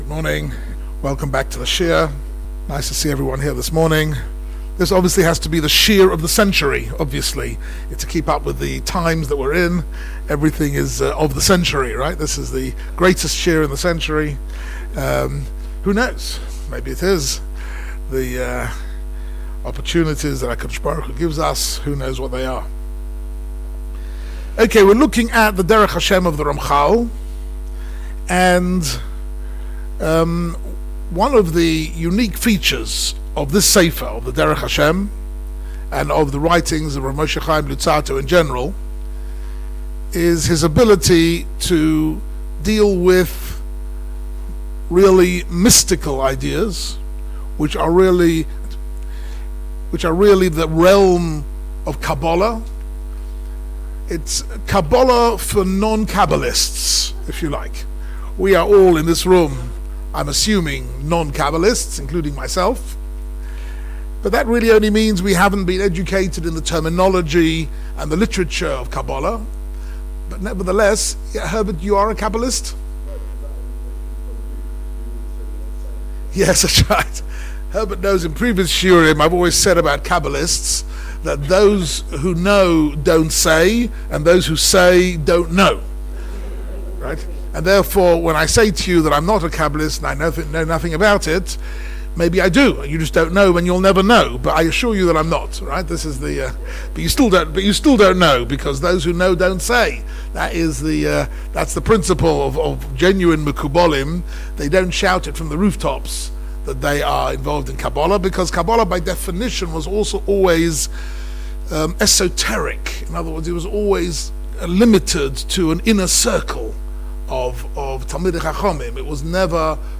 Delivered at the OU Israel Center, January 29, 2020, 3 Shevat 5780